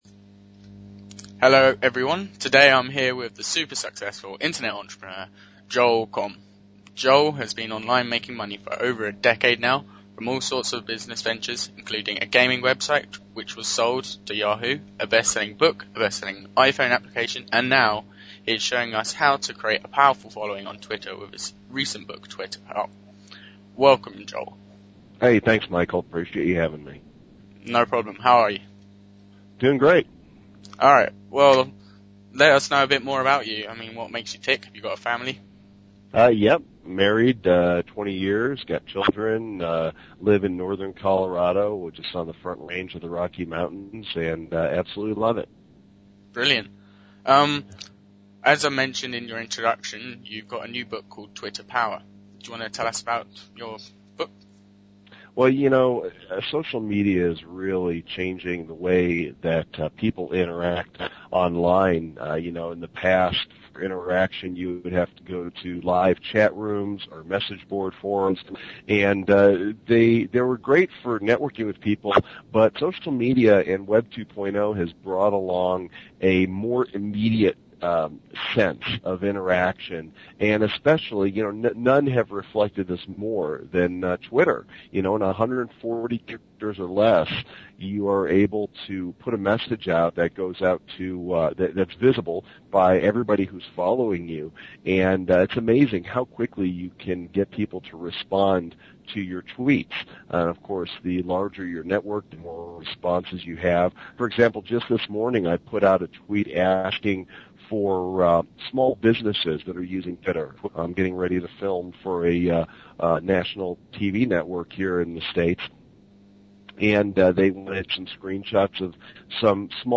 Top Selling Internet Entrepreneur